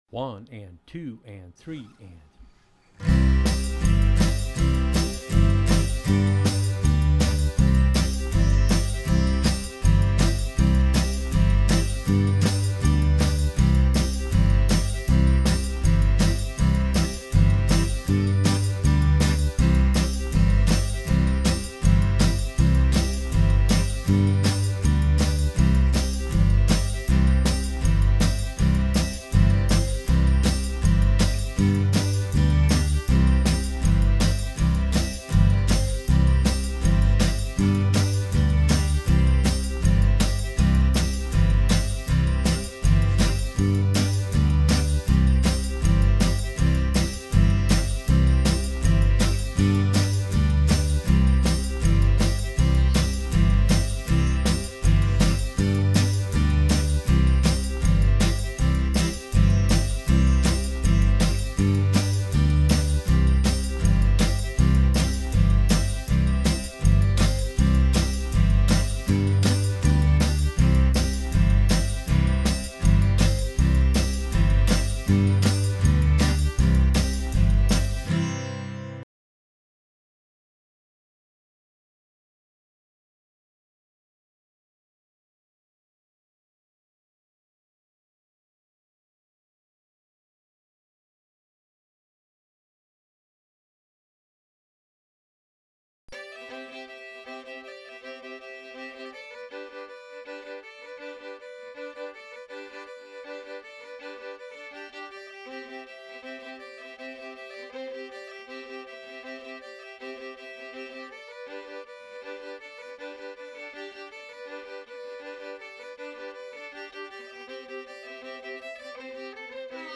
Intro Cajun Accordion Lessons